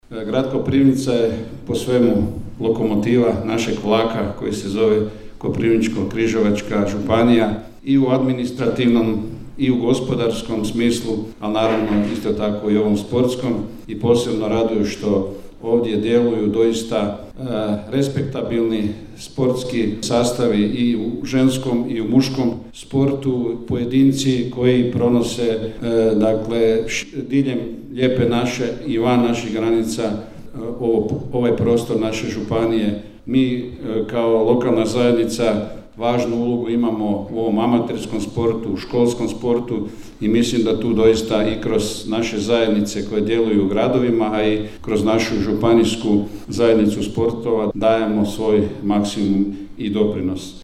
U Domu mladih proglašeni su najuspješniji sportaši, sportašice i sportske ekipe grada Koprivnice u 2024. godini.
Prisutne je pozdravio i koprivničko-križevački župan Darko Koren istaknuvši važnost sporta kao dijela života svake zajednice;